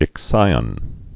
(ĭk-sīən, ĭksē-ŏn)